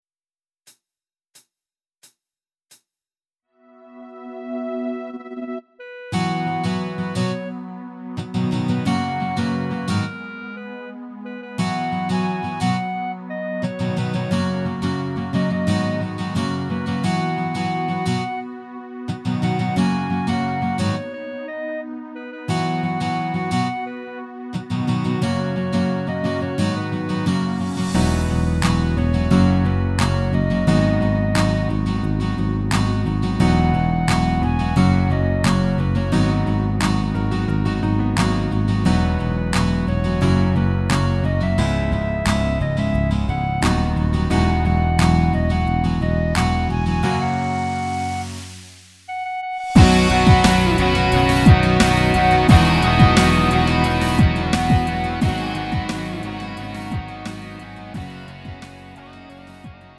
-멜로디MR 가수
음정 -1키 장르 가요